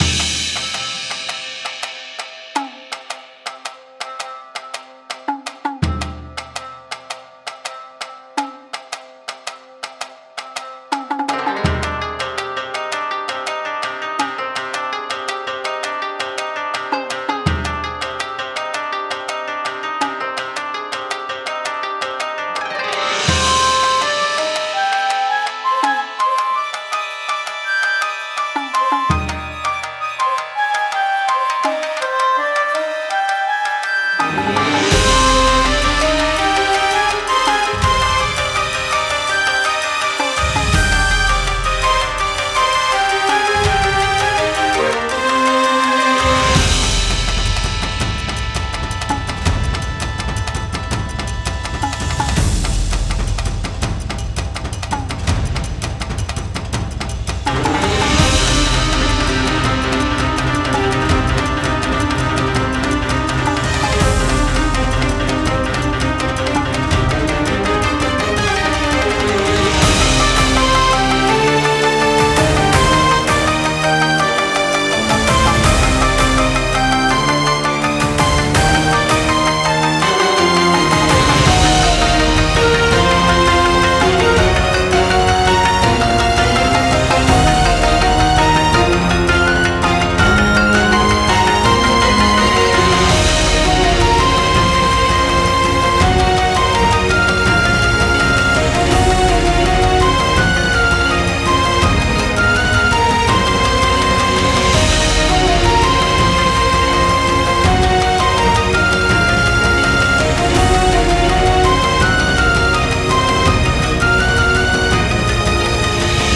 和風ボス戦オーケストラBGM